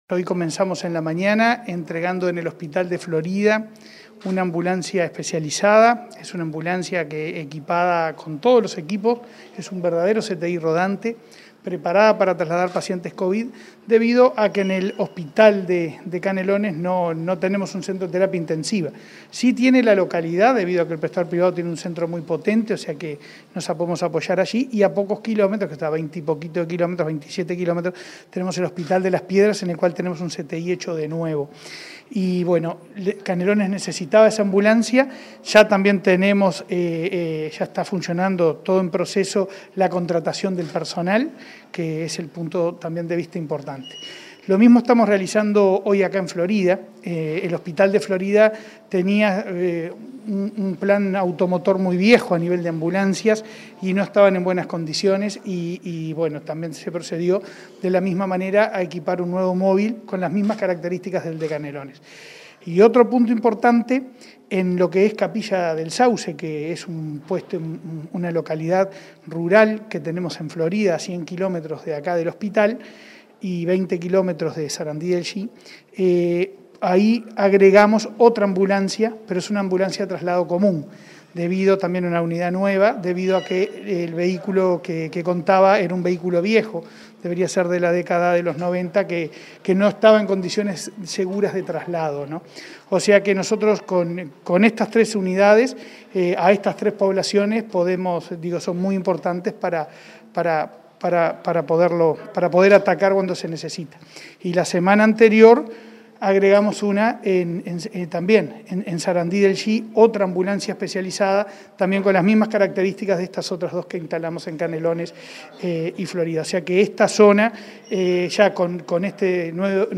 Entrevista al presidente de ASSE, Leonardo Cipriani, en Florida